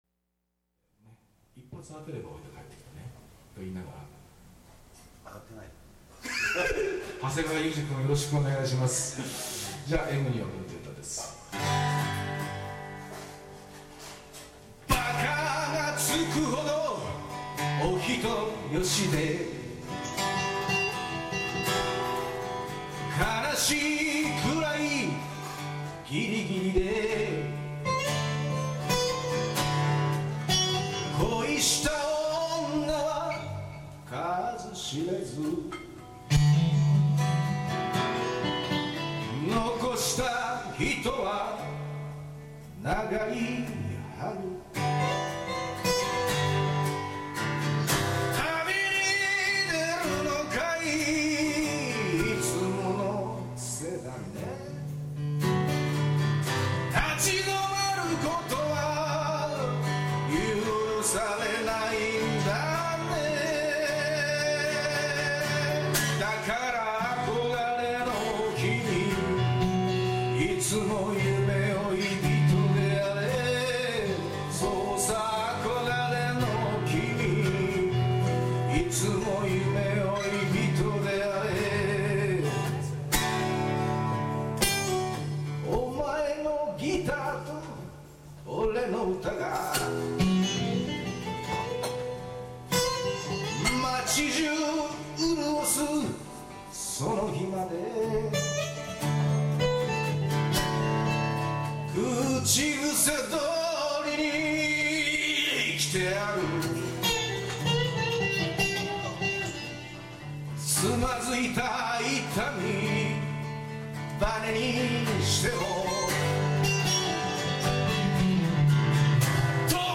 東京でのライブ音源探してみました・・・
数少ない・・・東京でのLIVE音源です。
僕の大好きなギタリストです。